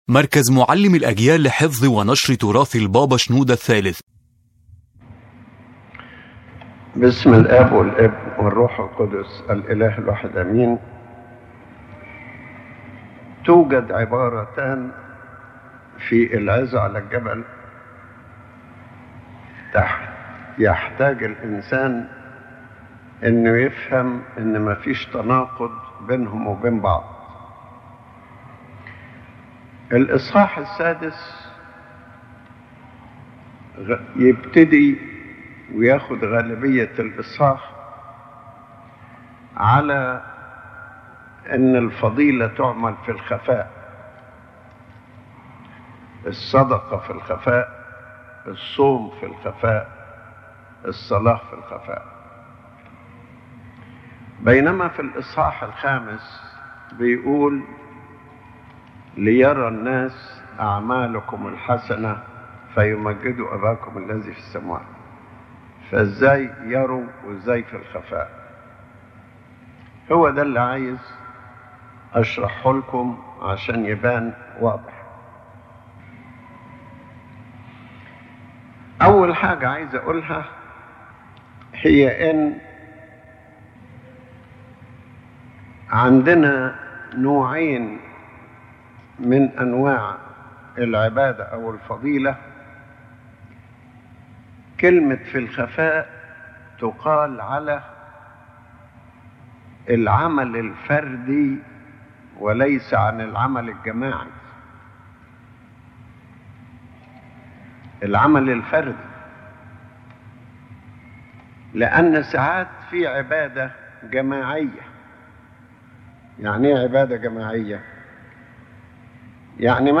This lecture explains the concept of spiritual work in secret as taught by the Lord Jesus Christ. It clarifies that there is no contradiction between doing virtue in secret and allowing good works to be seen when the goal is the glory of God, not self-glory.